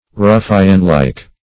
Ruffianlike \Ruf"fian*like`\, a.
ruffianlike.mp3